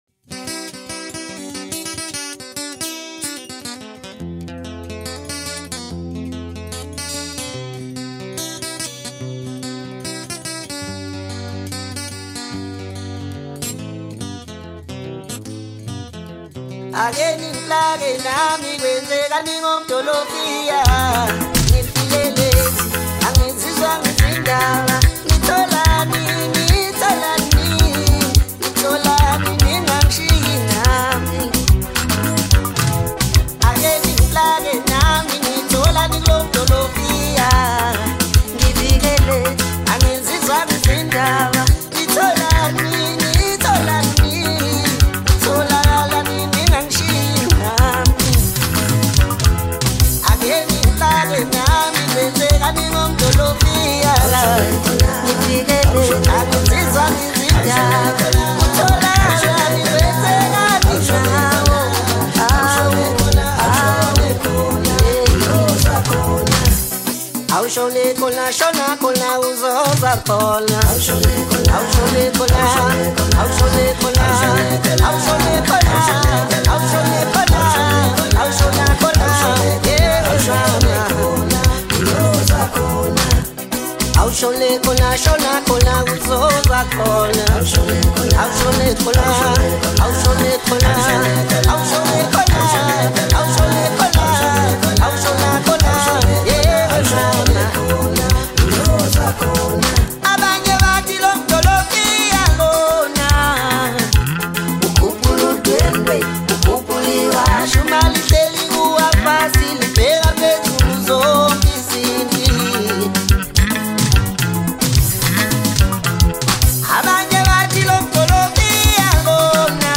Home » Maskandi » DJ Mix